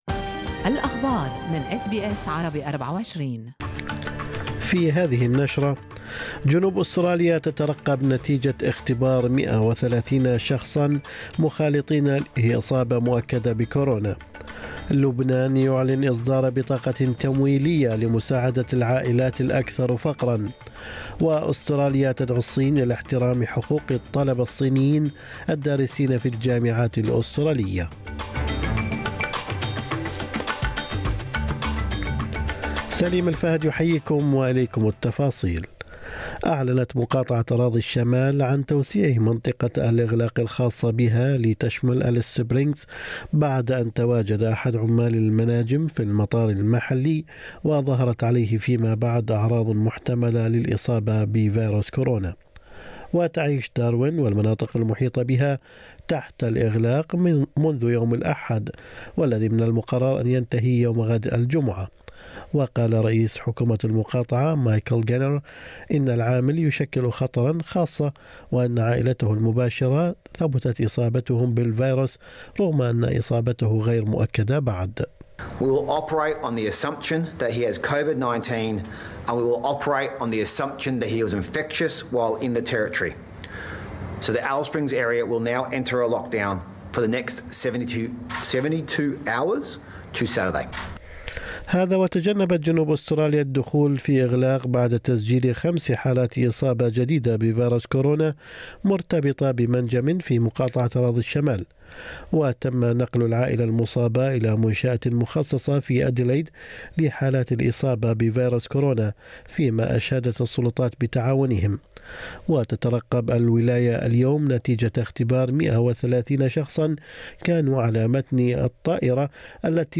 نشرة أخبار الصباح 1/7/2021